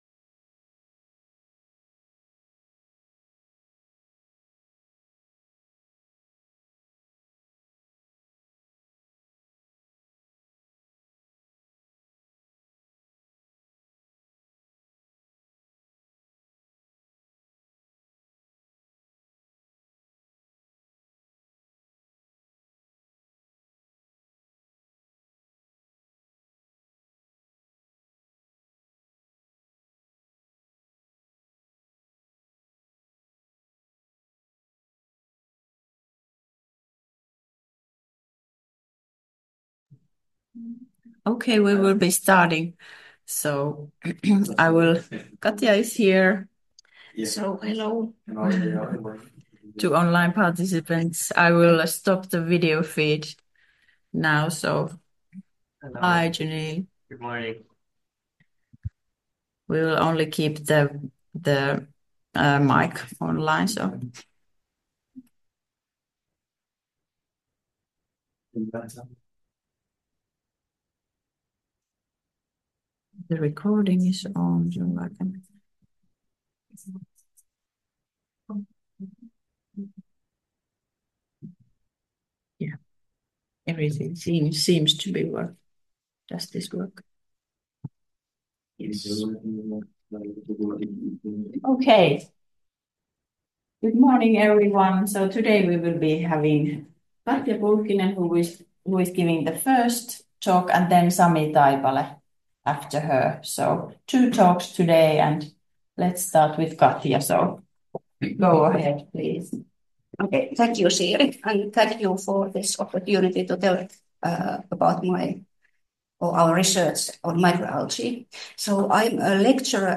Research presentations